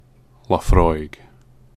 Laphroaig distillery (/ləˈfrɔɪɡ/
lə-FROYG)[5] is a single malt Scotch whisky distillery on Islay, Scotland.